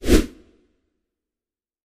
slide.mp3